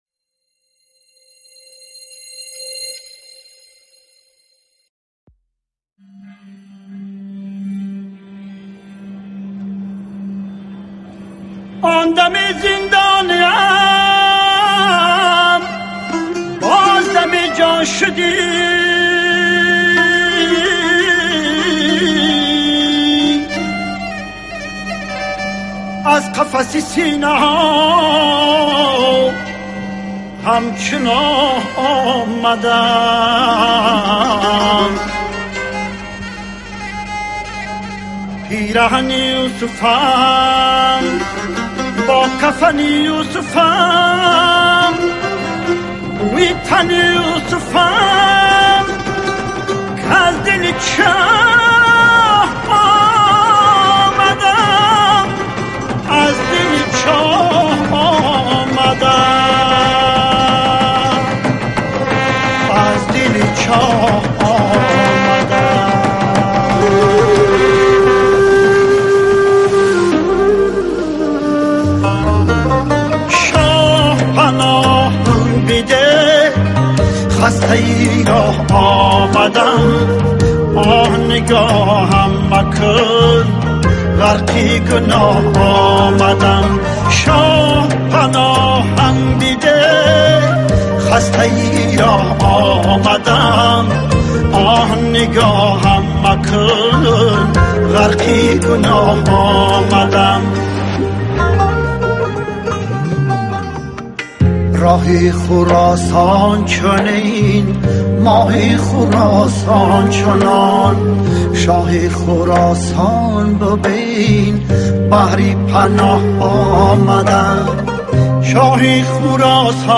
• مولودی